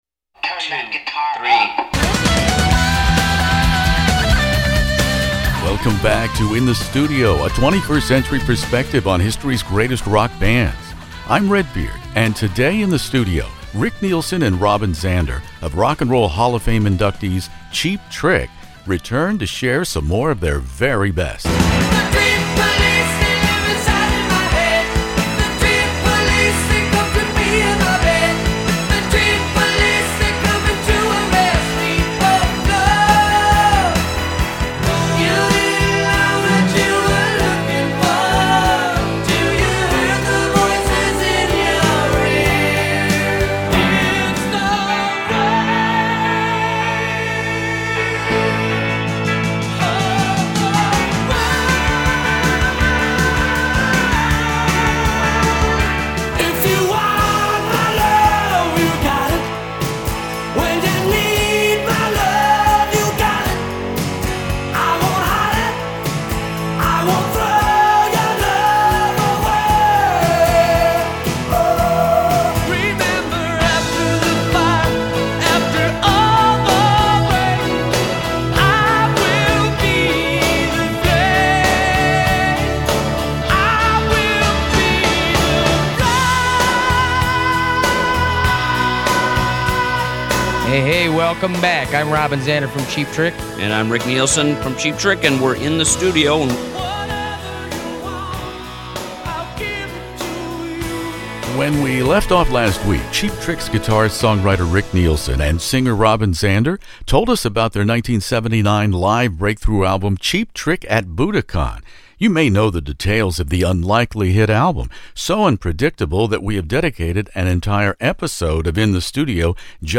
One of the world's largest classic rock interview archives, from ACDC to ZZ Top, by award-winning radio personality Redbeard.
Lifers Robin Zander and Rick Nielsen were basking in the glow of “The Flame” when they joined me In the Studio.